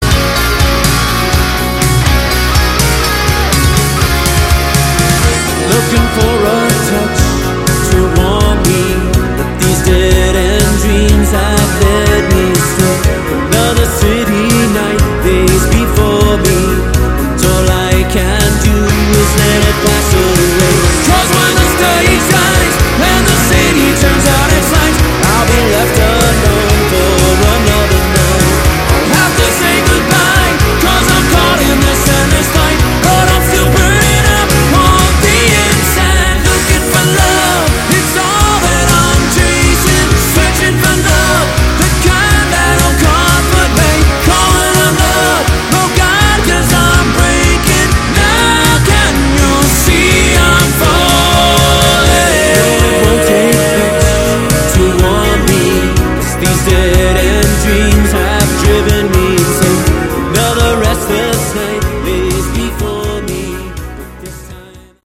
Category: AOR
vocals
guitars
bass
drums